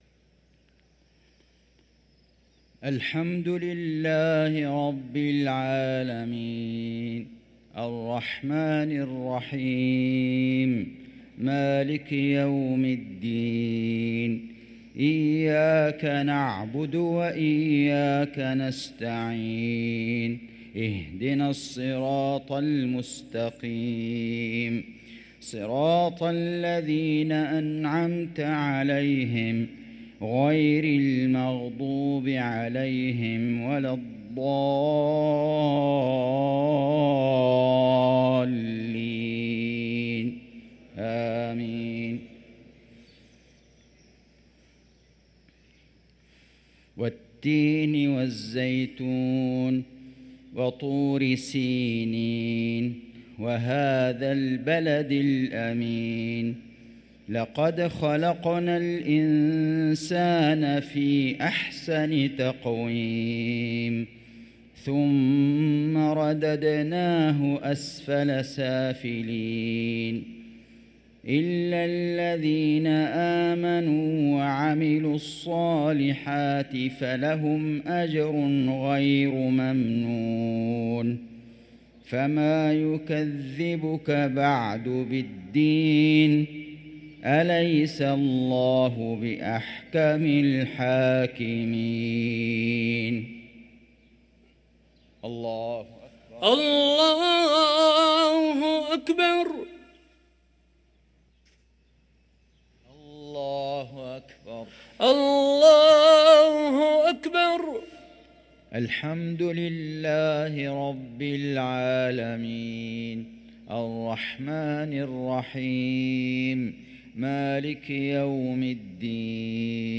صلاة المغرب للقارئ فيصل غزاوي 21 جمادي الأول 1445 هـ
تِلَاوَات الْحَرَمَيْن .